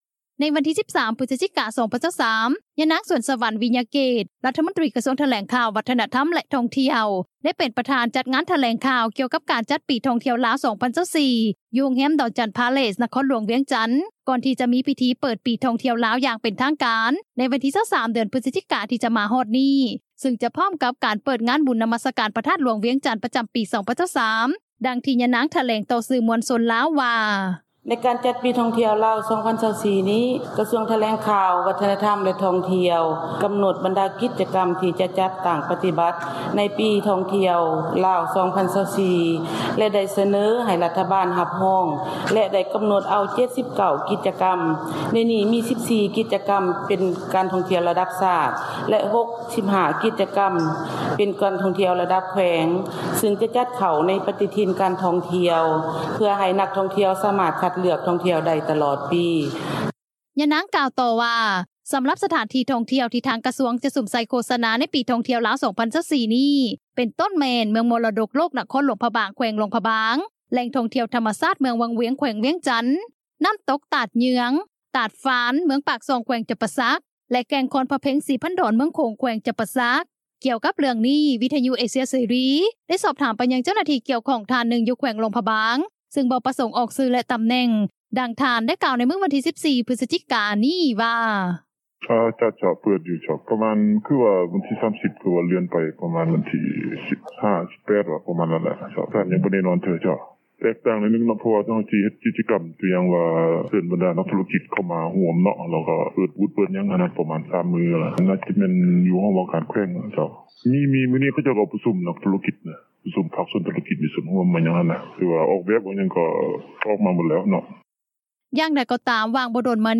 ທາງດ້ານ ນັກທ່ອງທ່ຽວຊາວໄທຍ ນາງນຶ່ງ ກ່າວວ່າ ການບໍຣິຫານຈັດການ ການທ່ອງທ່ຽວ ຂອງຣັຖບານລາວ ຍັງບໍ່ທັນເປັນລະບົບລະບຽບປານໃດ ໂຕຢ່າງເຊັ່ນ ເມືອງວັງວຽງ ແຂວງວຽງຈັນ.